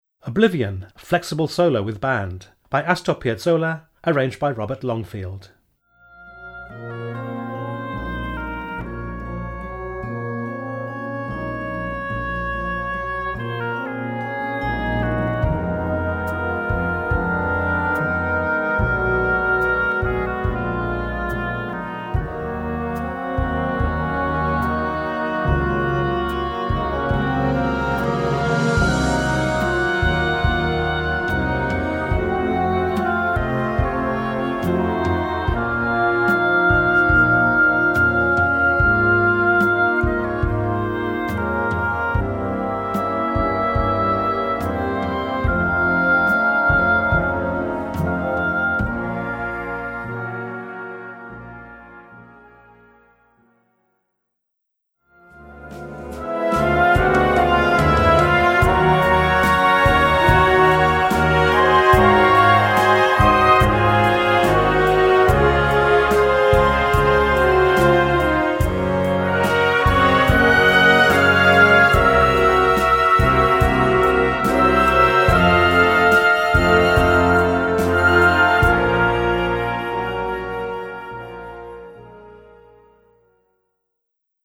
Gattung: Flexible Solo with Band
Besetzung: Blasorchester